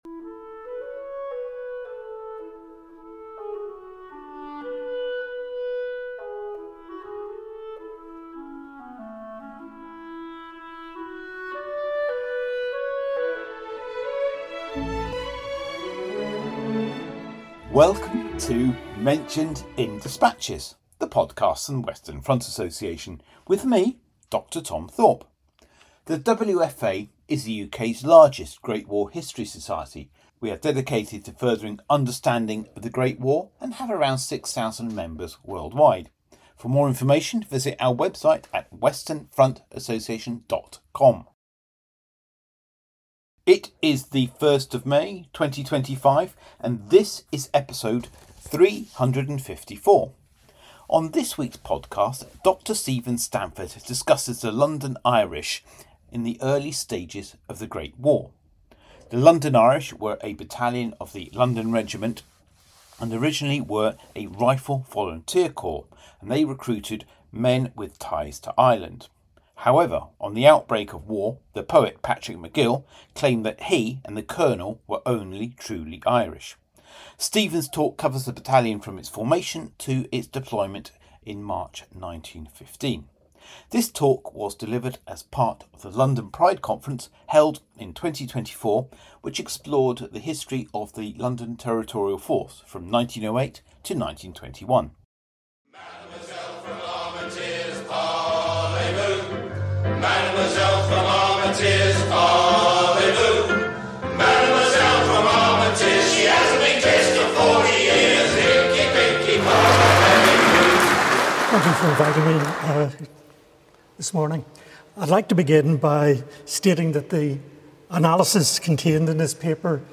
He examines the battalion’s formation, recruitment, training and unique cultural identity, as well as its connections to Ireland and its first action at Loos. This talk was recorded at the London Pride Conference, held in June 2024.